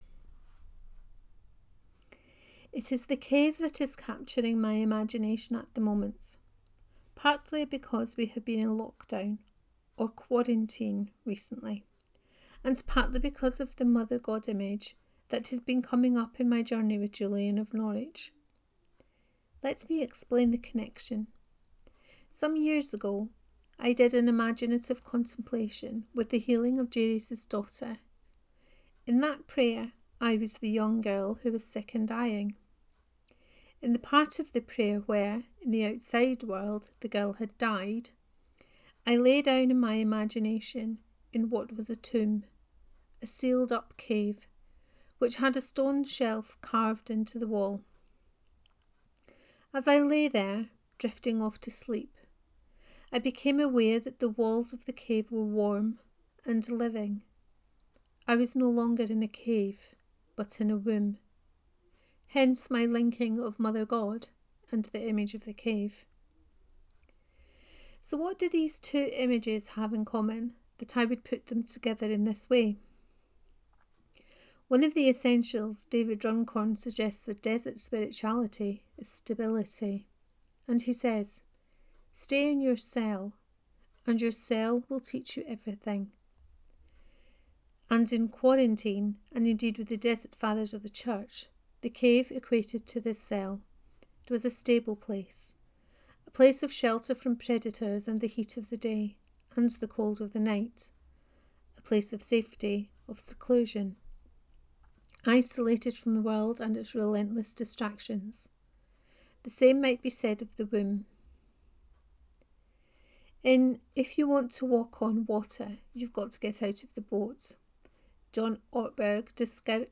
On Being in the Cave 2: Reading of this post.